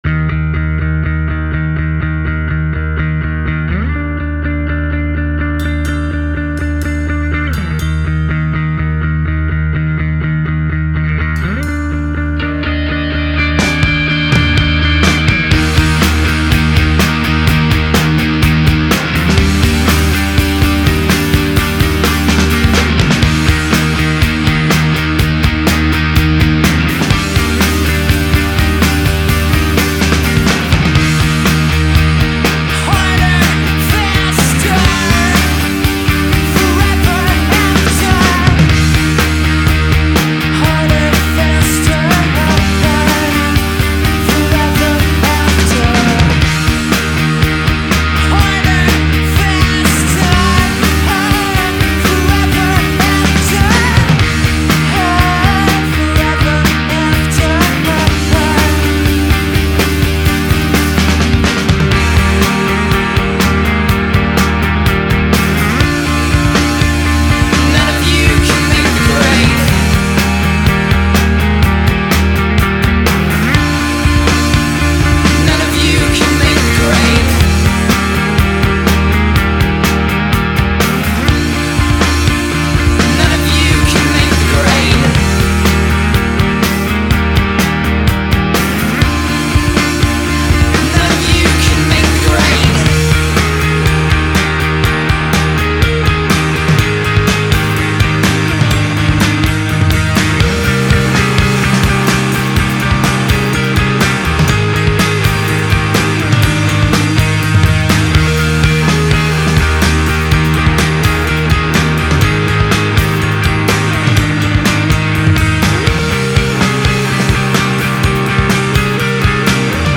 Рок Альтернативный рок Alternative